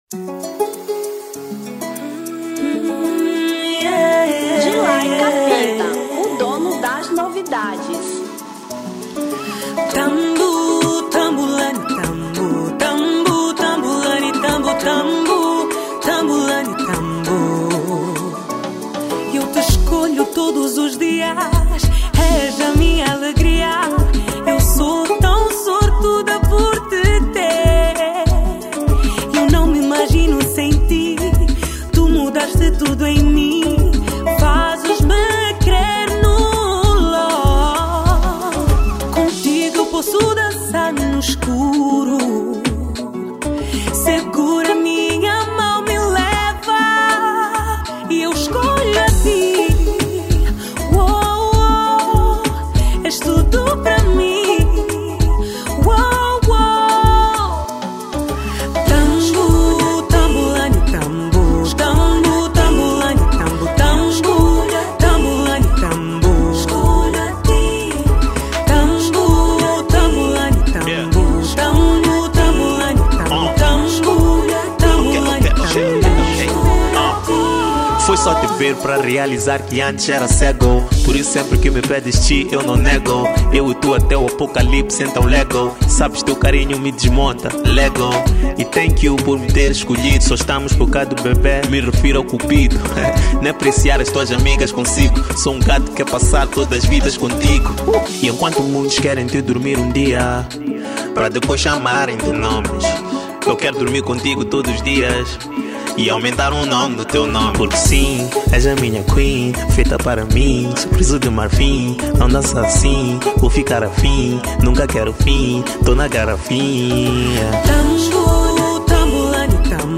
Kizomba 2025